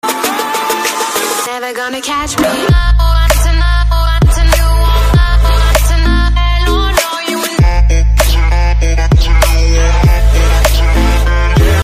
Kategori POP